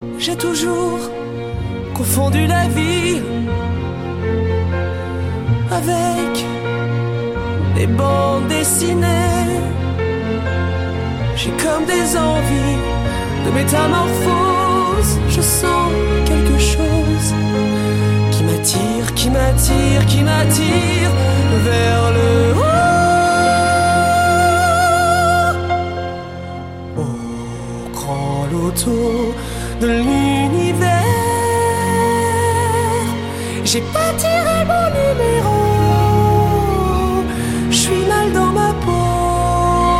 красивый мужской голос
поп